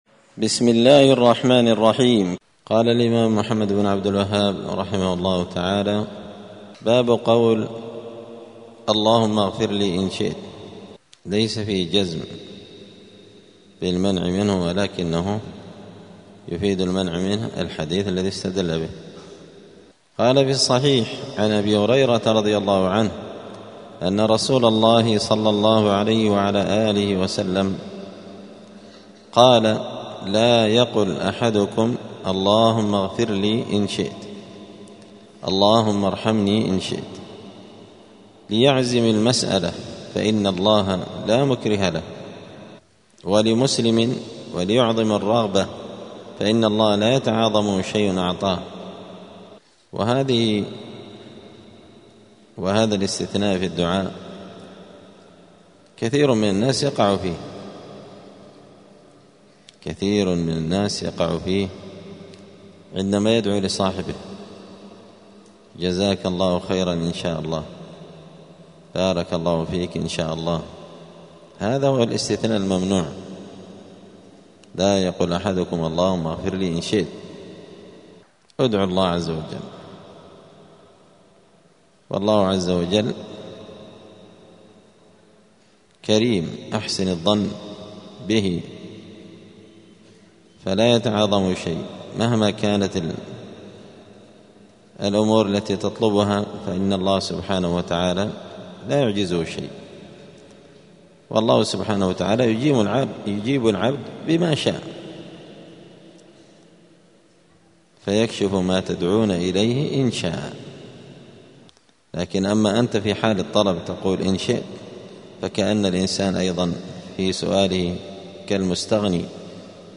دار الحديث السلفية بمسجد الفرقان قشن المهرة اليمن
*الدرس الثالث والثلاثون بعد المائة (133) {باب قول اللهم اغفر لي إن شئت}*